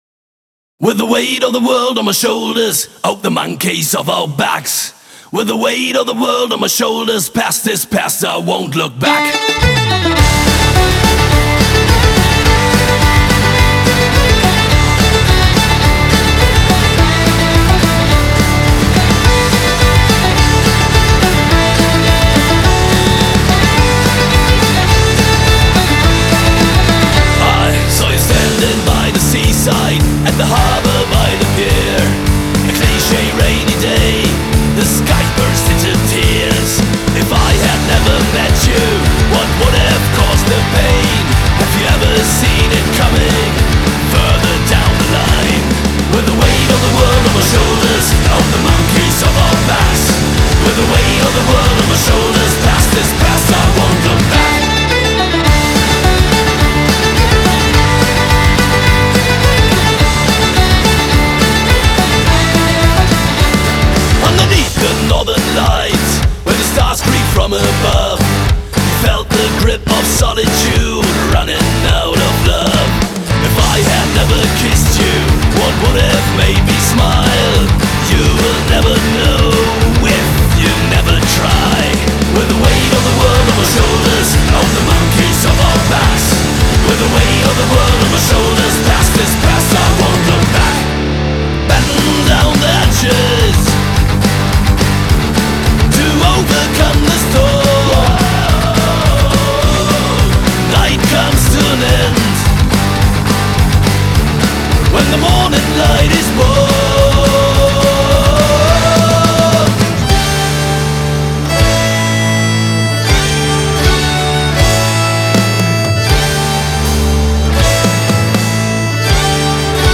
Genre: Rock-Folk